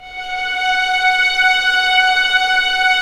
Index of /90_sSampleCDs/Roland L-CD702/VOL-1/STR_Orchestral p/STR_Orchestral p